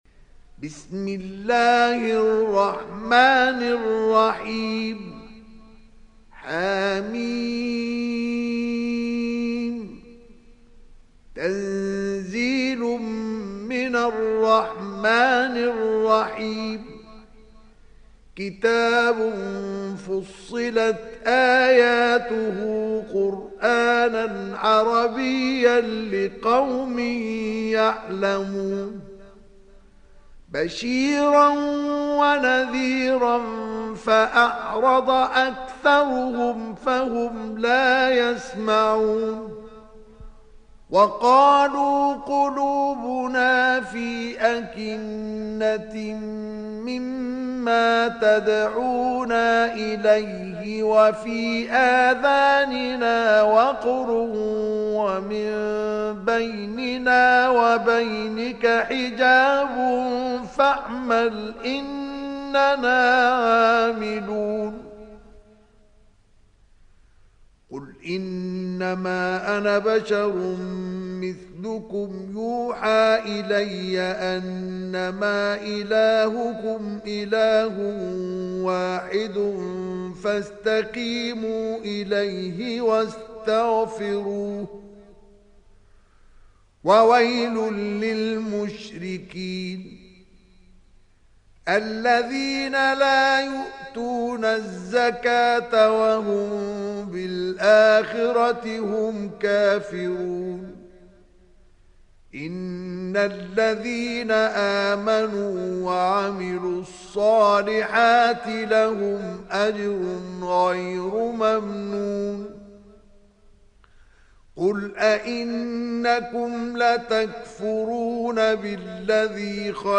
Surat Fussilat Download mp3 Mustafa Ismail Riwayat Hafs dari Asim, Download Quran dan mendengarkan mp3 tautan langsung penuh